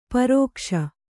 ♪ parōkṣa